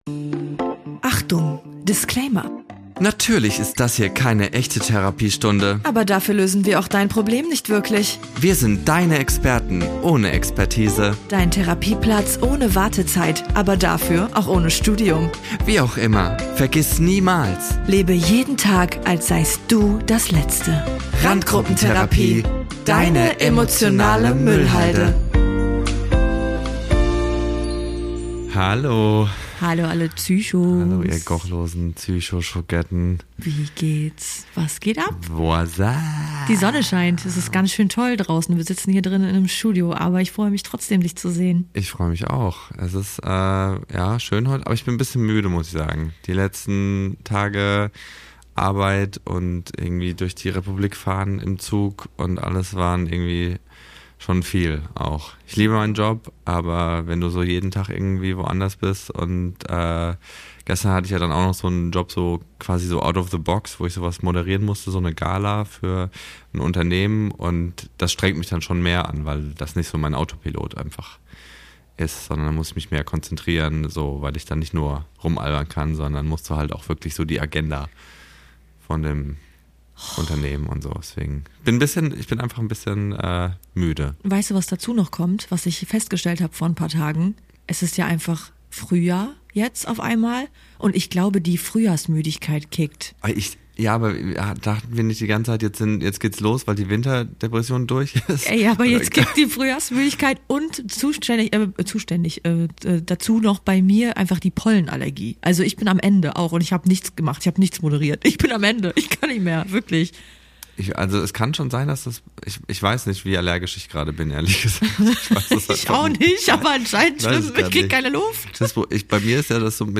Simon Stäblein und Natasha Kimberly sind beste Freunde und besprechen in ihrem Podcast ihren eigenen Struggel und den ihrer Hörerinnen und Hörer.